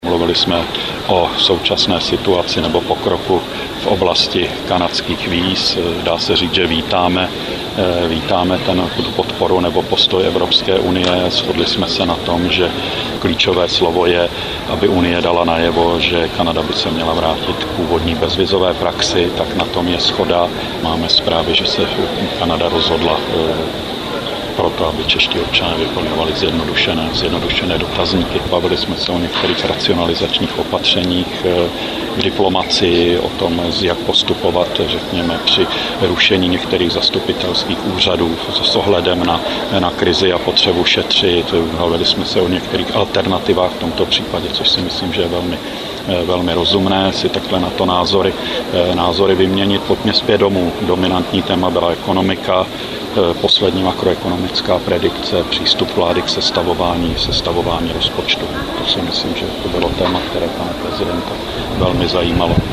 Premiér Jan Fischer po schůzce s prezidentem Václavem Klausem